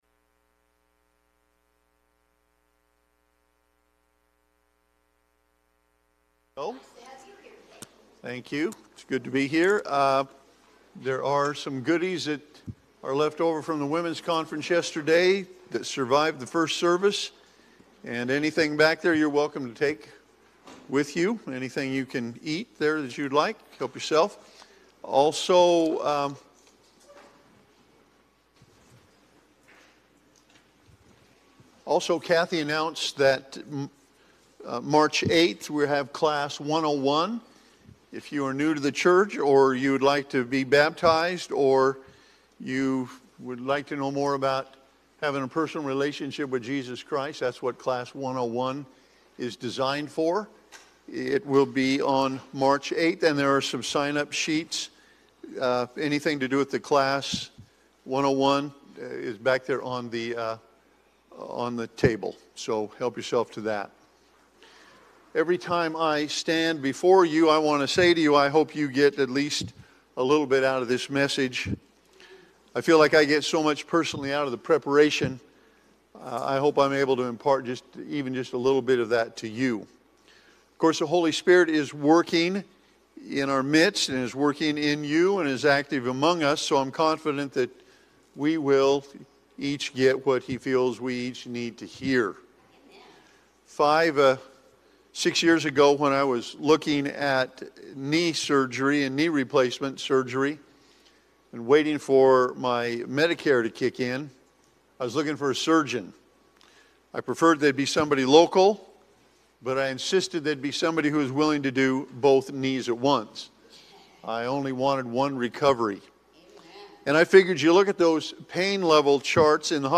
2-22-Sermon-Audio.mp3